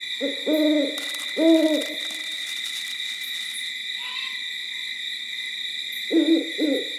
Un livre sonore pour écouter  les animaux de la nuit : le hibou, le hérisson, le rossignol, les grenouilles, le miaulement et le ronronnement du chat… et s’endormir.
Les sons vont au-delà des simples cris des animaux. Ils restituent l’univers de la nuit.